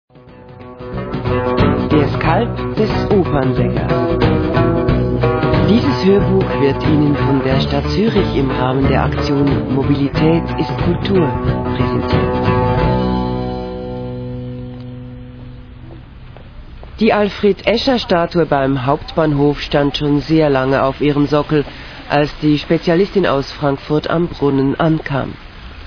Hörspiele als roter Faden für Stadtrundgang in Zürich.